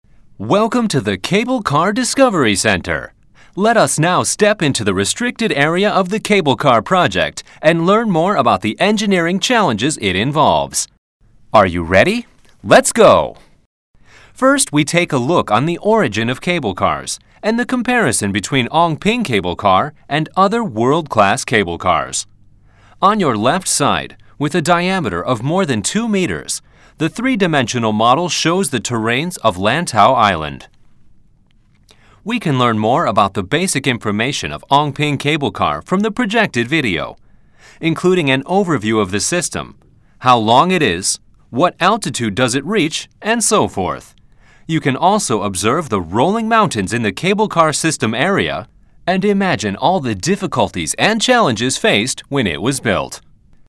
Cable Car Discovery Centre Audio Guide (English)